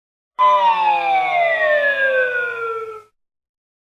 Play, download and share Bank Rupt original sound button!!!!
wheel-of-fortune-bankrupt_rnEkLXf.mp3